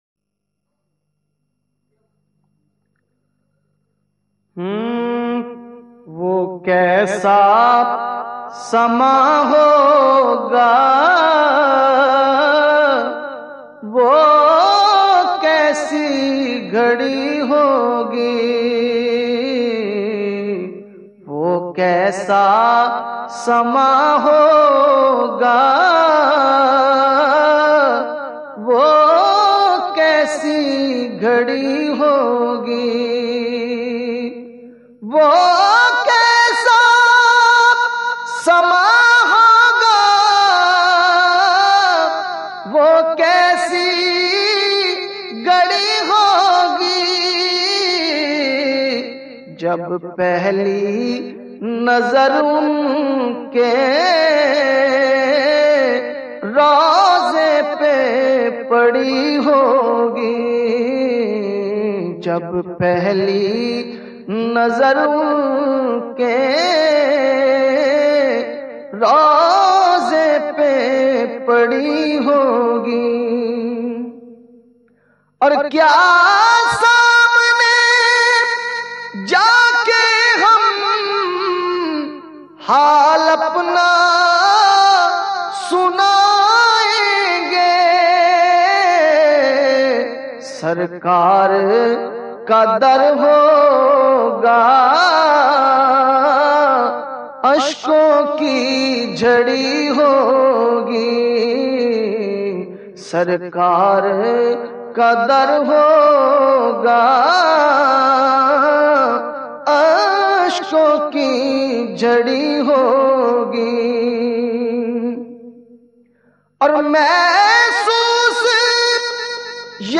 Naat MP3